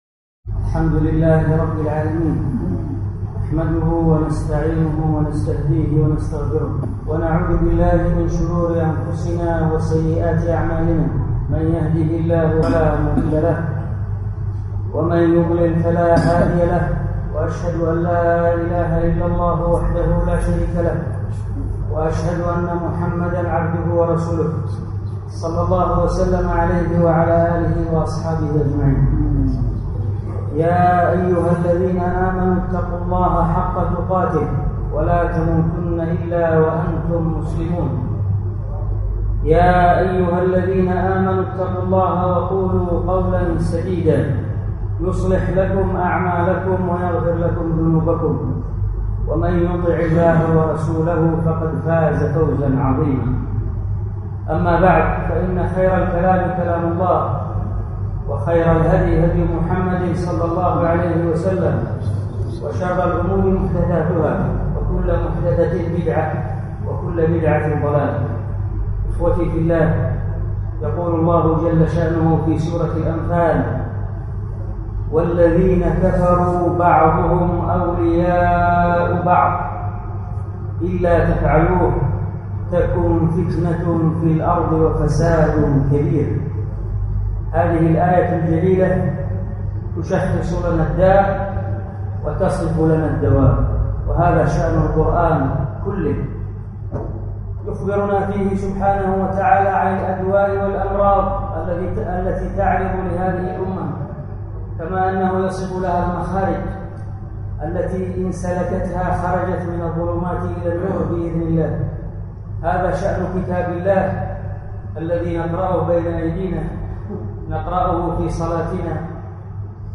الخطبه